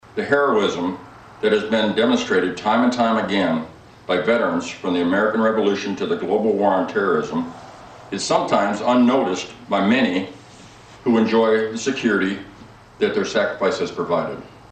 The Coldwater American Legion Post #52, which was founded in 1919, held their annual Veterans Day ceremony which was followed by an open house for the community.